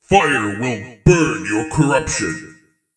SentryFire.wav